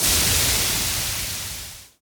Waterspray 1.ogg